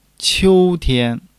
qiu1-tian1.mp3